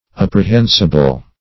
Apprehensible \Ap`pre*hen"si*ble\, a. [L. apprehensibilis. See